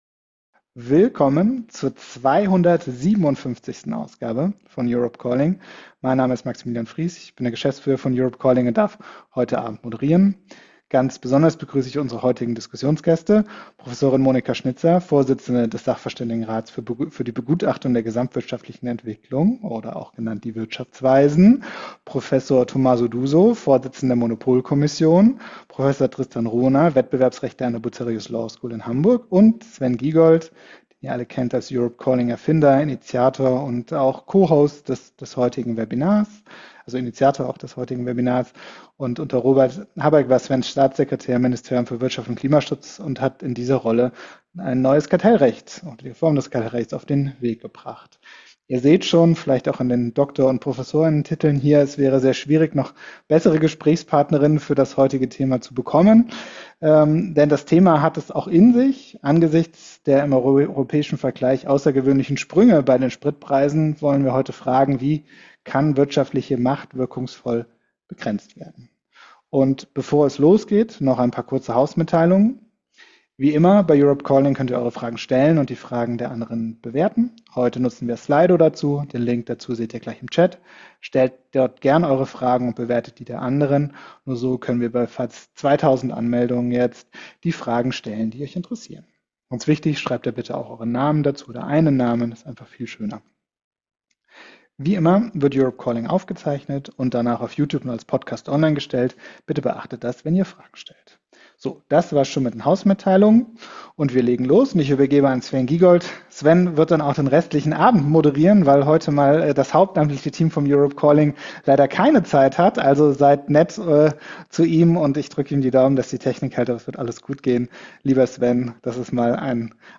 Aufzeichnung des 257. Webinar von Europe Calling am 19.03.2026 mit folgenden Gästen: